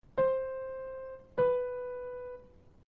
المسافات الصوتية الثنائية
استمع إلى المسافات الثنائية التالية ثم حدد إن كانت صاعدة أو نازلة